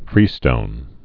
(frēstōn)